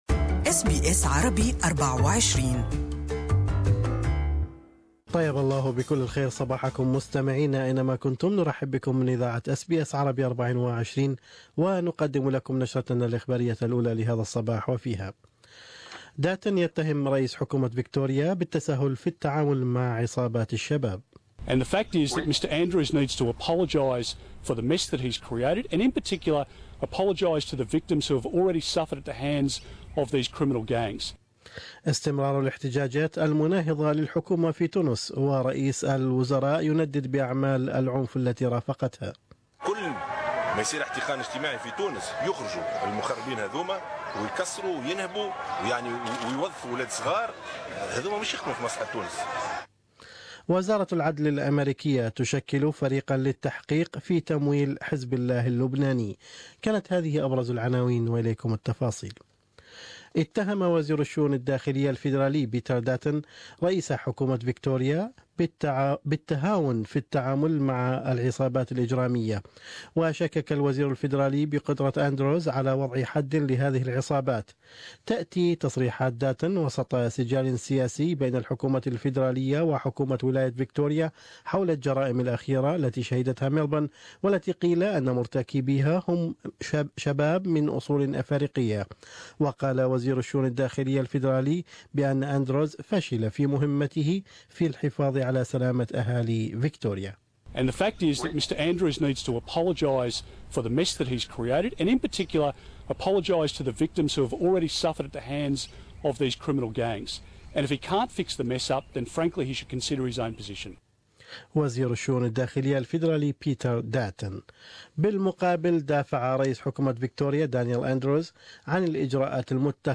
العنوان الابرز في نشرة الانباء صباح اليوم هو: - أتهام وزير الشؤون الداخلية الفيدرالي بيتر داتون لرئيس حكومة فيكتوريا دانيال أندروز بالتهاون في التعامل مع عصابات الشباب.